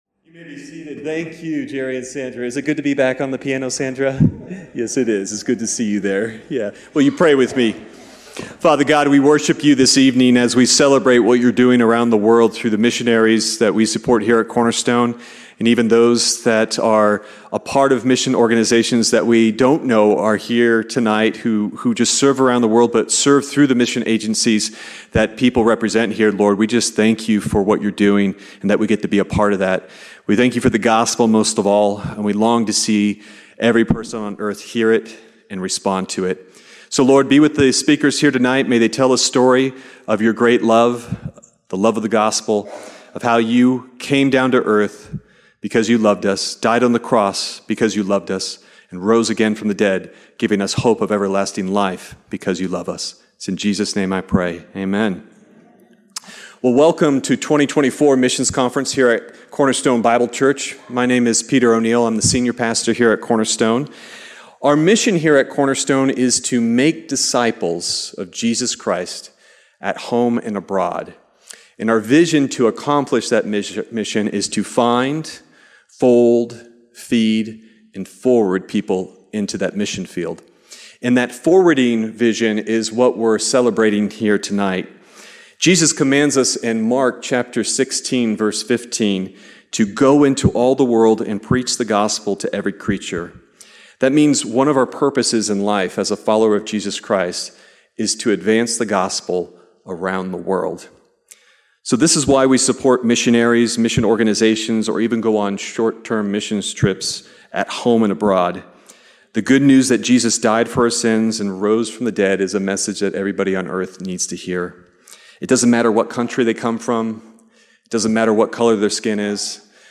Sermon Detail
Missions Conference Friday 2024